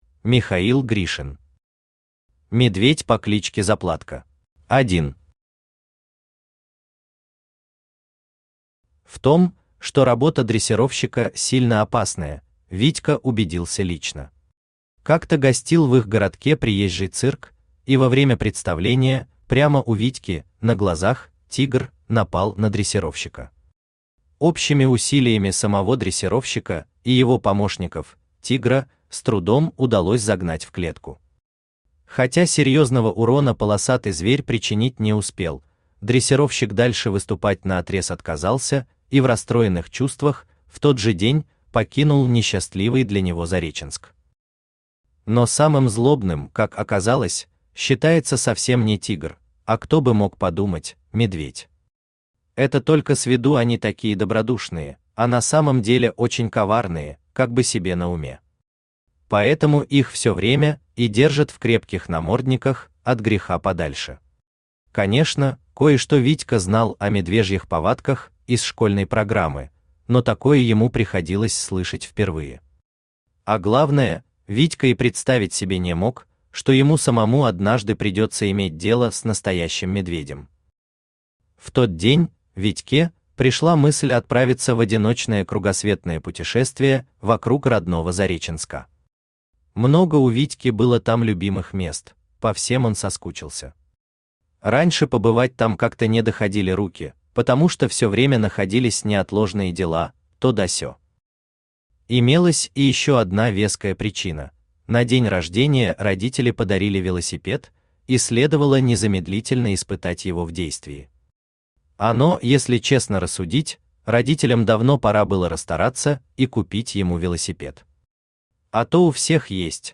Aудиокнига Медведь по кличке Заплатка Автор Михаил Анатольевич Гришин Читает аудиокнигу Авточтец ЛитРес. Прослушать и бесплатно скачать фрагмент аудиокниги